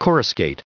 added pronounciation and merriam webster audio
1736_coruscate.ogg